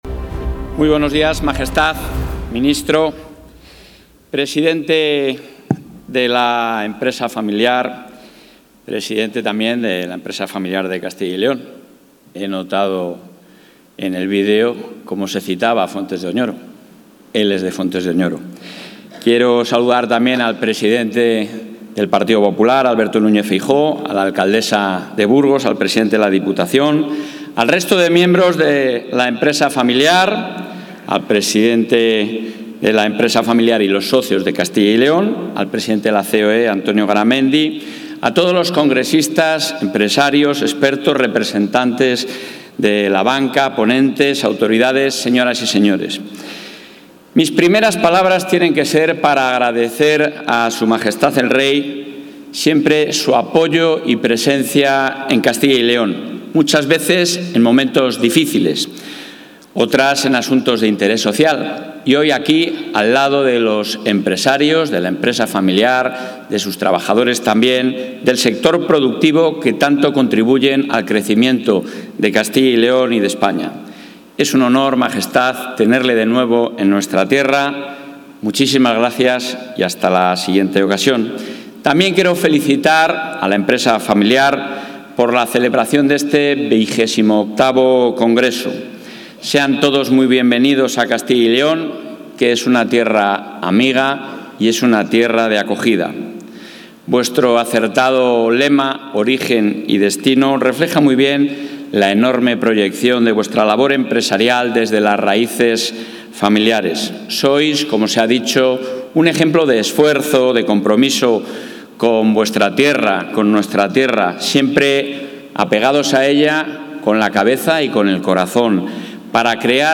Intervención del presidente de la Junta.
El presidente de la Junta de Castilla y León, Alfonso Fernández Mañueco, ha asistido hoy en Burgos al XXVIII Congreso Nacional de Empresa Familiar 'Origen Destino', presidido por Su Majestad el Rey Felipe VI, foro en el que ha destacado la labor del Instituto de Empresa Familiar y la contribución de estas entidades al dinamismo económico y a la cohesión territorial de la Comunidad, al ser fuente de riqueza y motor de empleo.